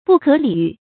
不可理喻 注音： ㄅㄨˋ ㄎㄜˇ ㄌㄧˇ ㄧㄩˋ 讀音讀法： 意思解釋： 理：道理；事理；喻：開導；使明白。